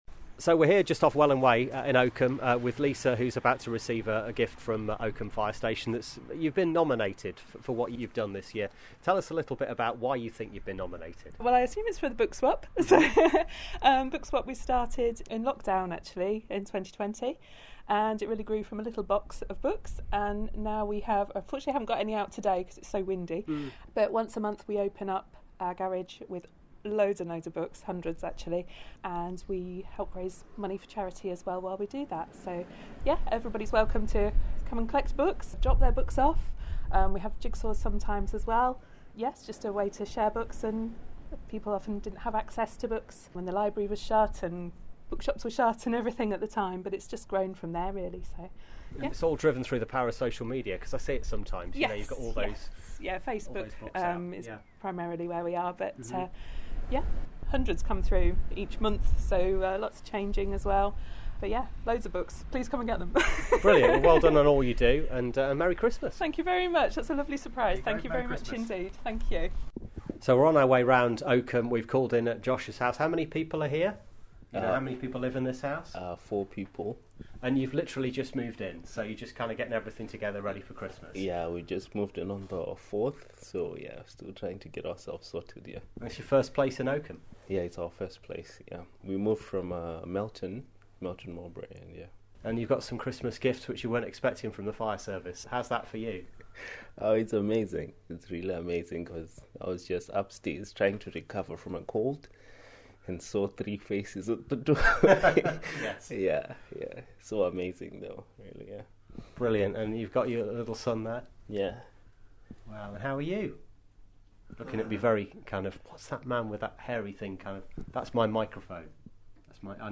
Hear from some people helping the community, some facing health problems and all very grateful to be thought of this Christmas: